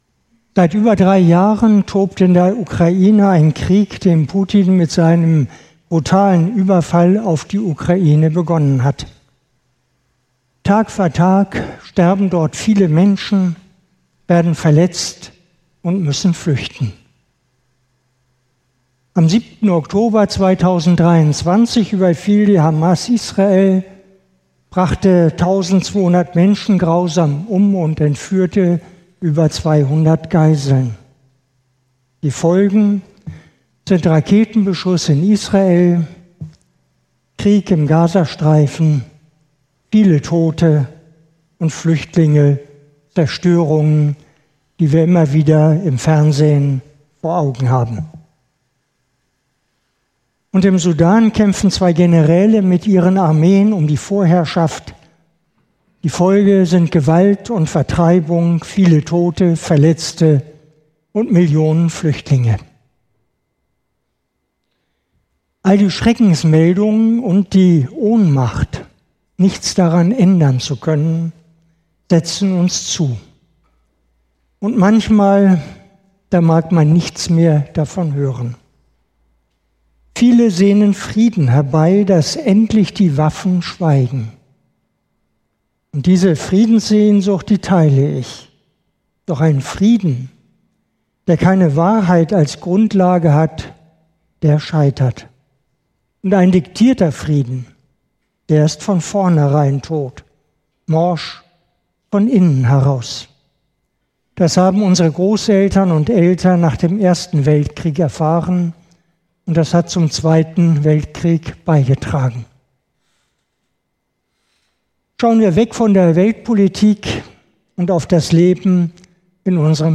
Predigen und andere Vorträge